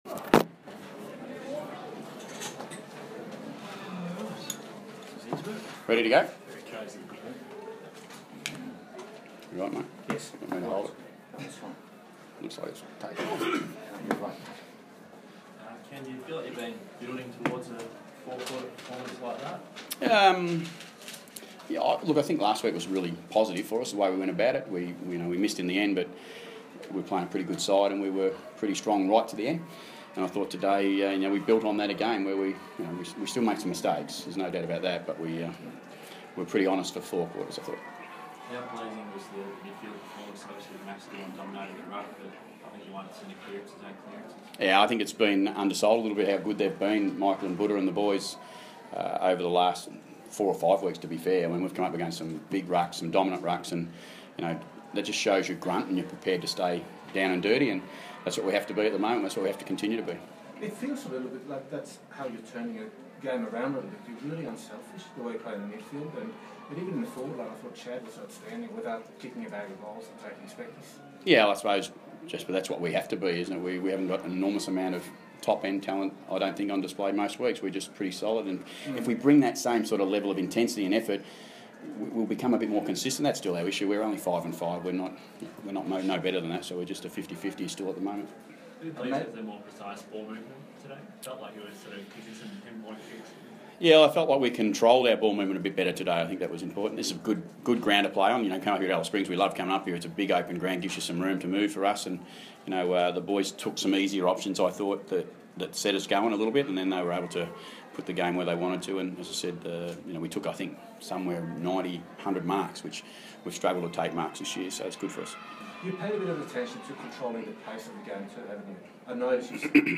Ken Hinkley Press Conference - Saturday, 28 May, 2016
Ken Hinkley talks with media after Port Adelaide's 45 point win over Melbourne in Alice Spring.